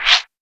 PERC.55.NEPT.wav